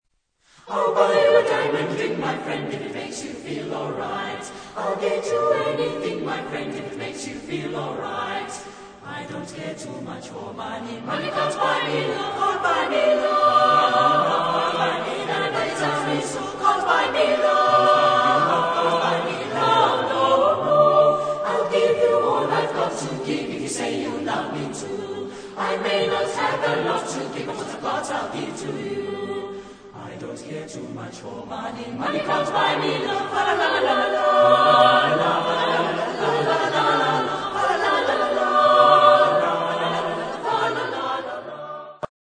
Genre-Stil-Form: Liedsatz ; Choraljazz ; weltlich
Chorgattung: SATB  (4 gemischter Chor Stimmen )